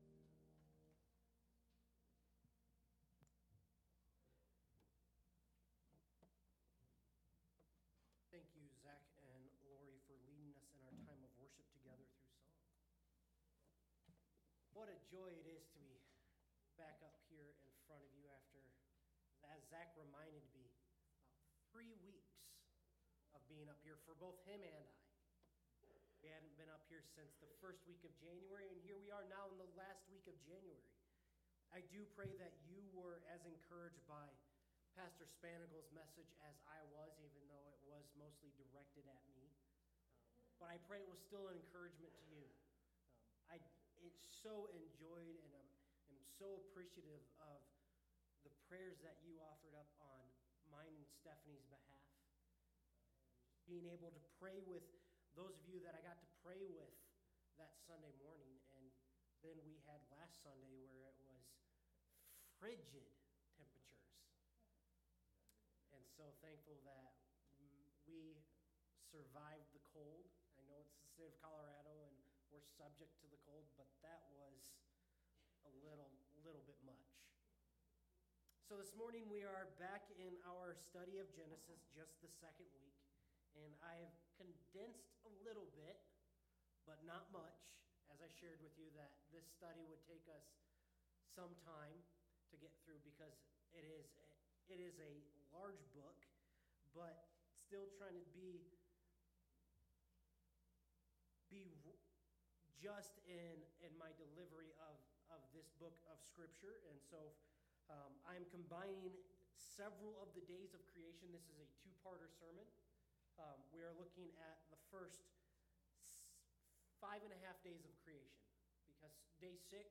Sermons by Aberdeen Baptist Church